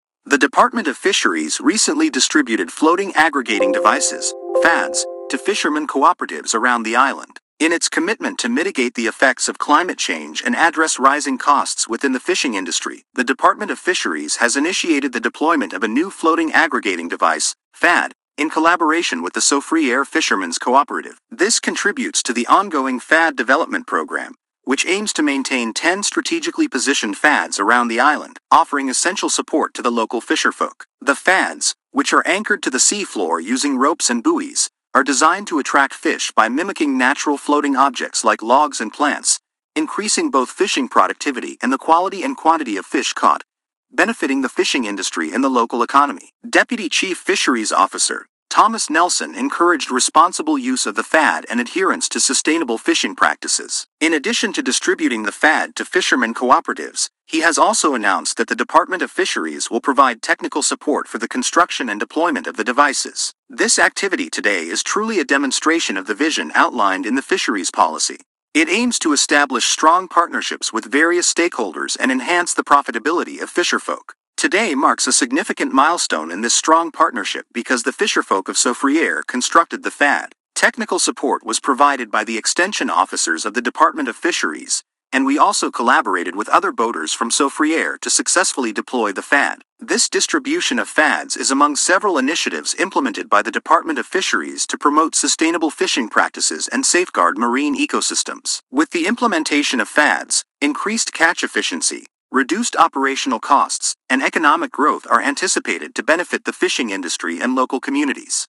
Play Press Release